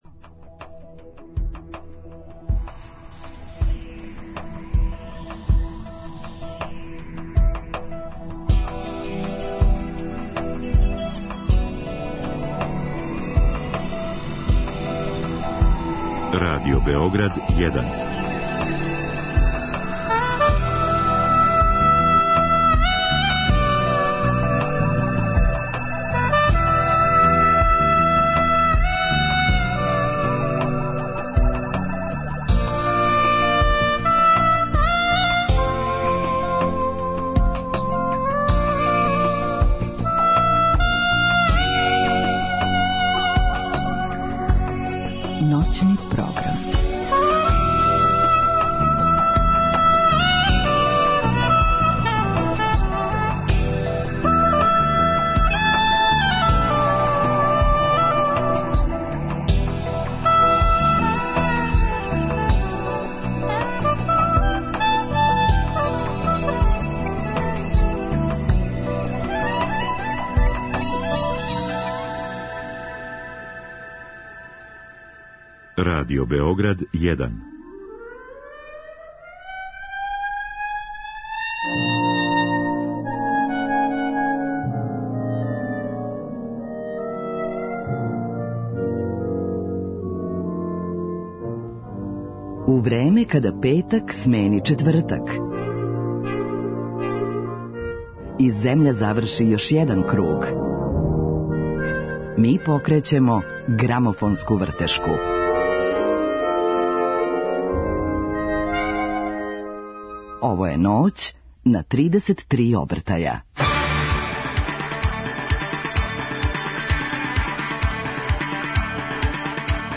Комисија за ширење позитивне енергије Радио Београда 1, донела је одлуку да ове ноћи ђускамо у ритму шлагера из 60их година.Њихаћемо кукове уз нумере које су југословенски певачи позајмиле од светских звезда!